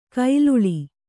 ♪ kailuḷi